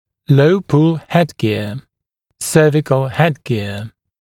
[ləu-pul ‘hedgɪə] [‘sɜːvɪkl ‘hedgɪə][лоу-пул ‘хэдгиа] [‘сё:викл ‘хэдгиа]лицевая дуга с низкой (шейной) тягой